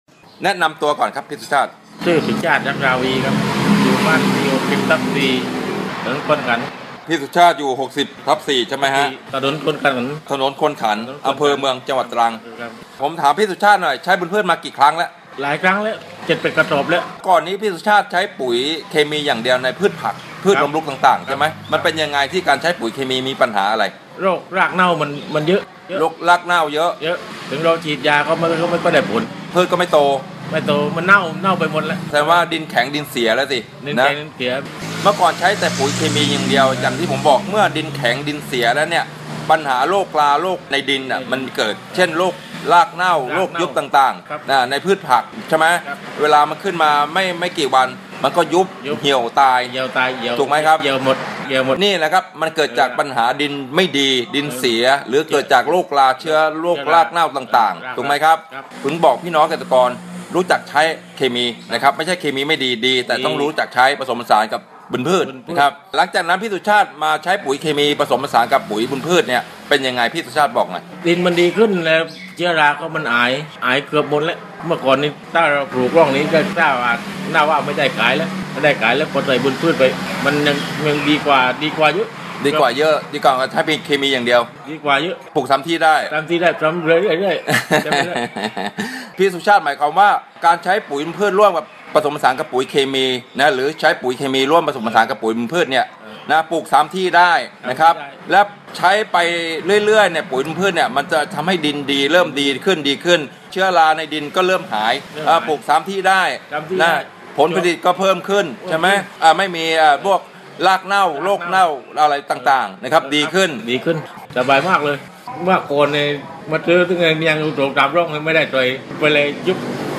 เสียงสัมภาษณ์เกษตรกร